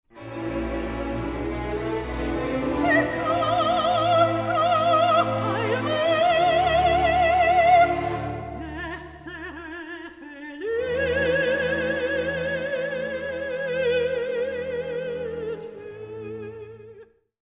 Rare French and Italian Opera Arias
Soprano
Released in stunning Super Audio CD surround sound.